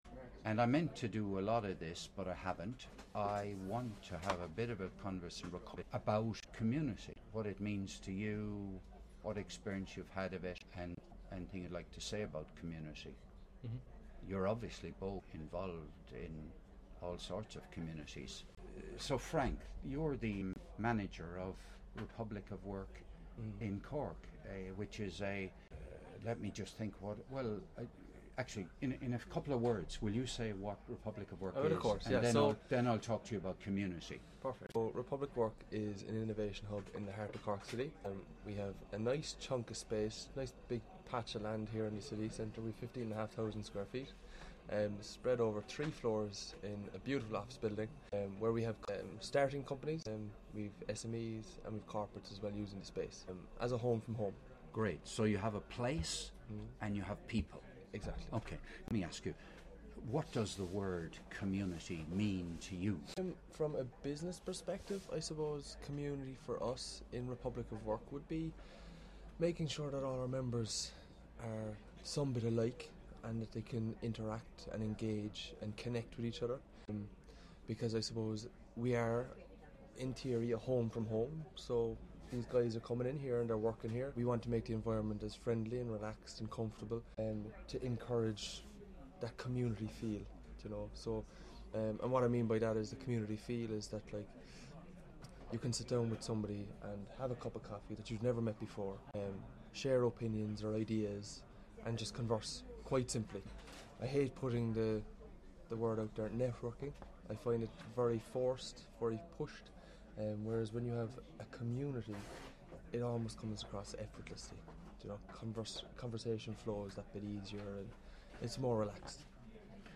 Recorded on 22 November 2019 in "Republic of Work" Cork Ireland.